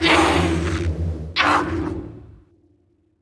Index of /App/sound/monster/spite_ghost